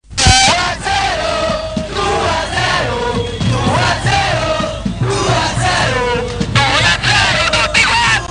Roma, Stadio Olimpico